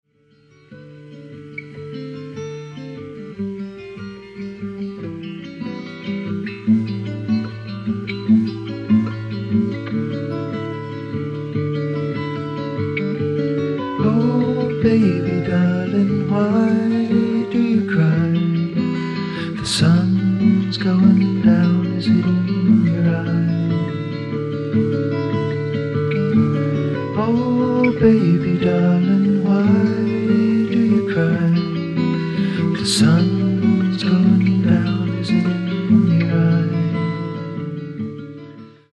Progressive Rock
デビュー作とは思えないほど、ファンキーかつハードなグルーヴを生み出している。